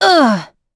Hilda-Vox_Damage_02.wav